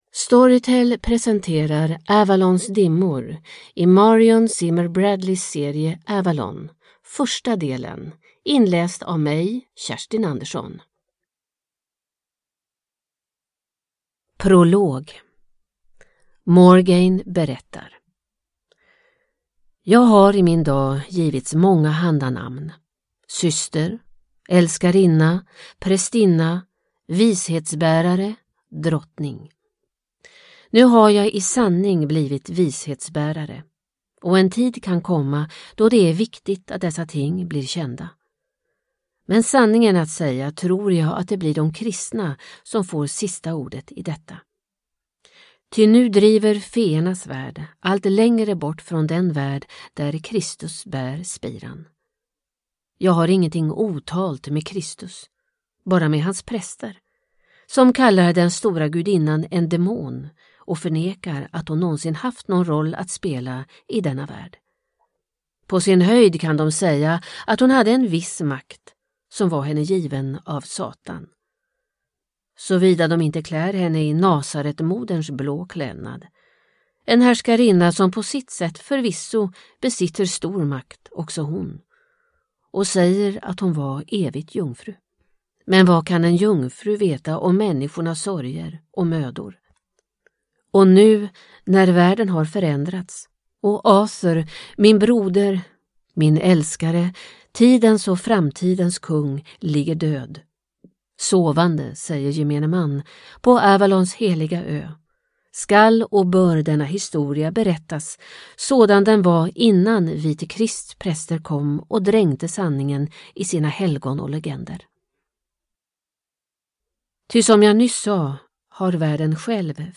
Avalons dimmor – del 1 – Ljudbok – Laddas ner